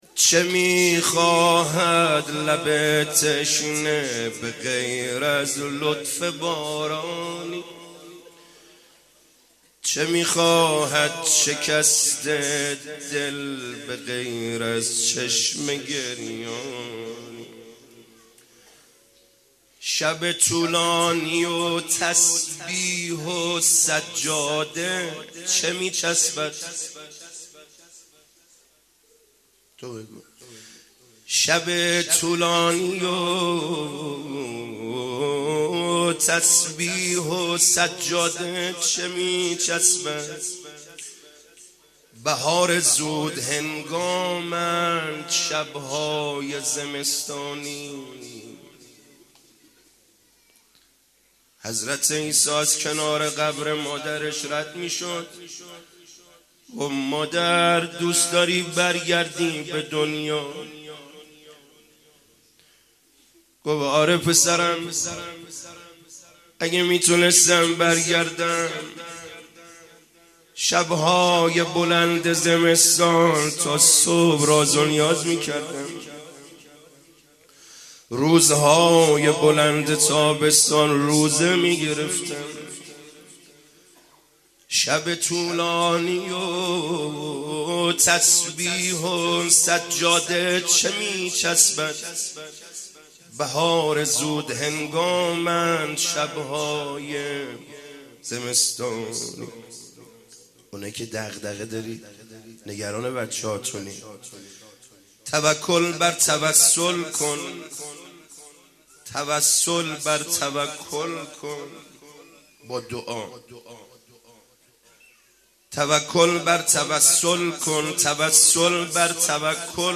مراسم شب چهارم فاطمیه 1398